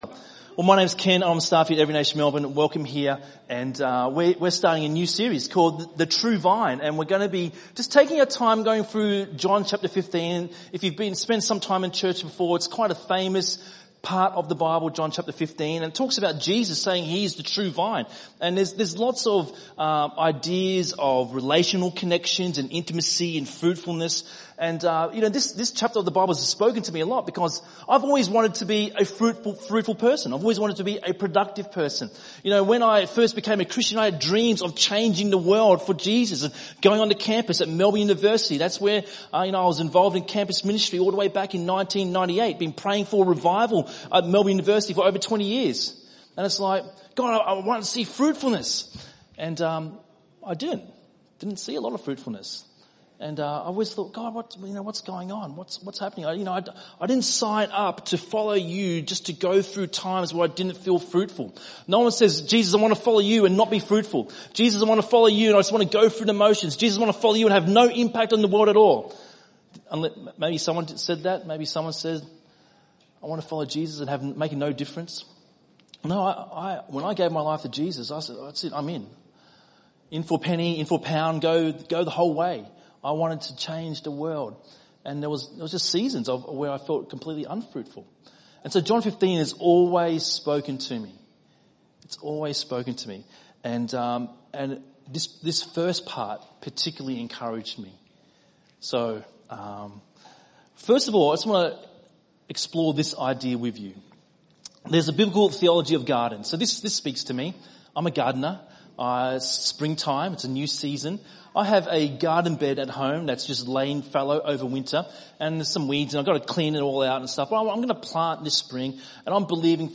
by enmelbourne | Sep 24, 2018 | ENM Sermon